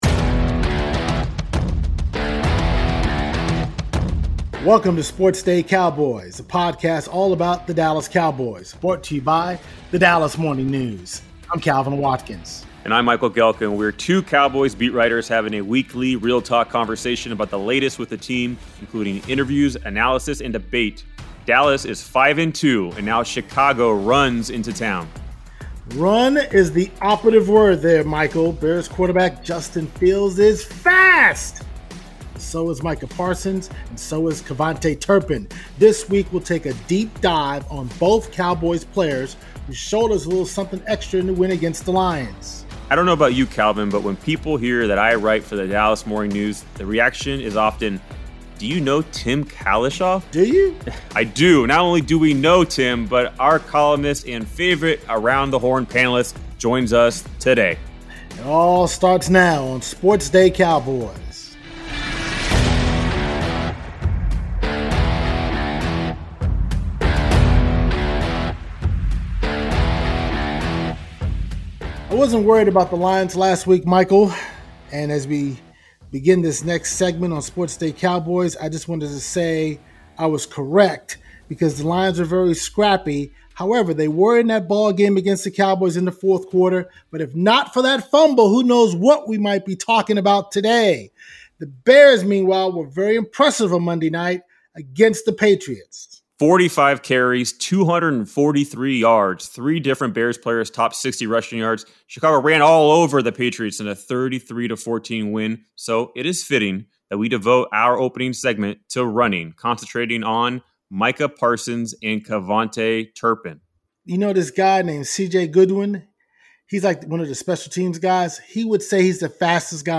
The Cowboys’ need for speed, interview with Tim Cowlishaw, trade deadline debate